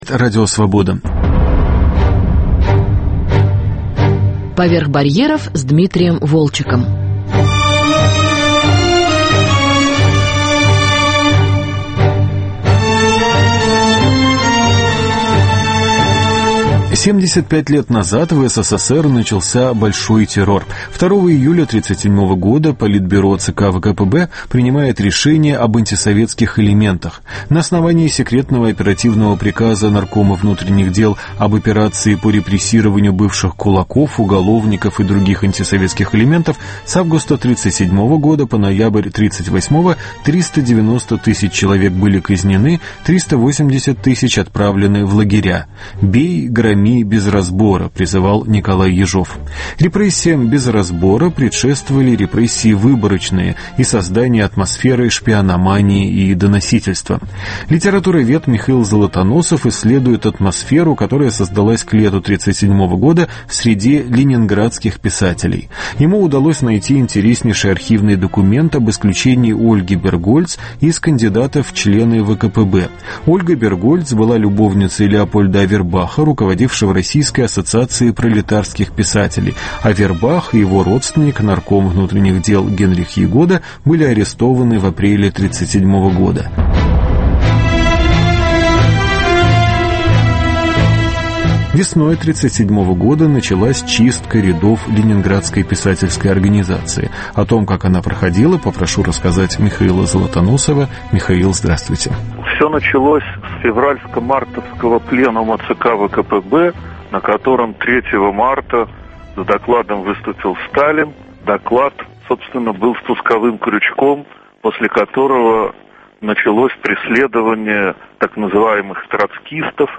Большой террор и ленинградские писатели. Беседа